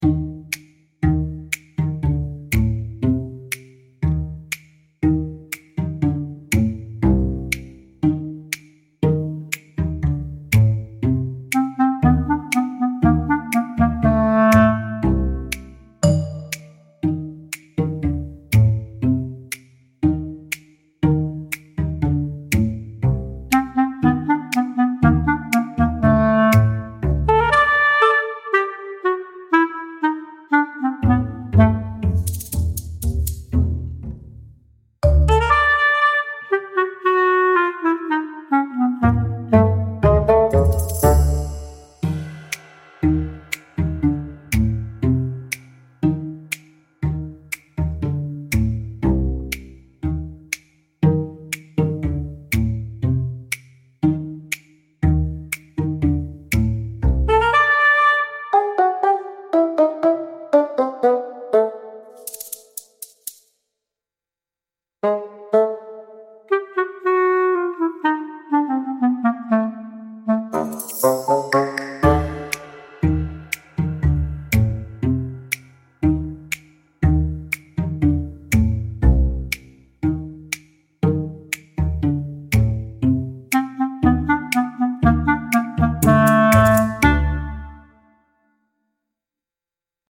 sneaky tiptoe music with muted trumpet, plucked bass and comedic pauses